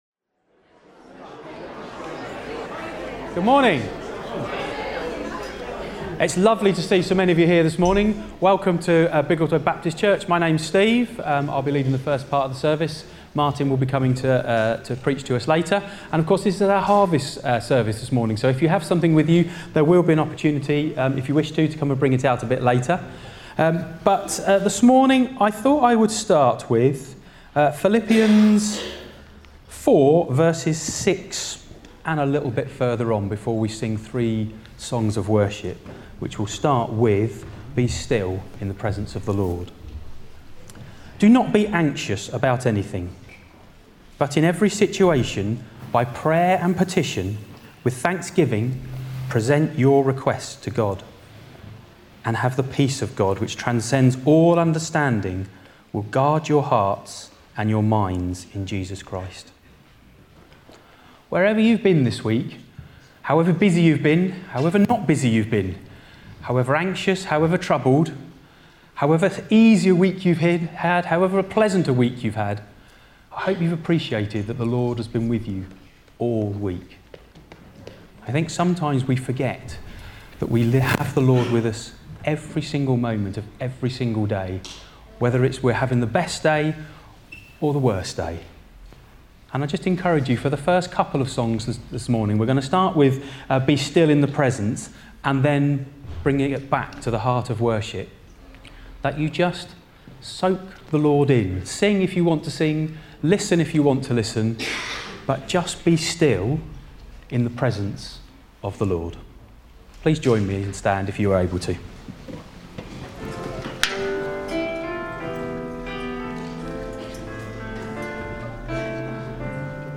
5 October 2025 – Morning Service
Service Type: Morning Service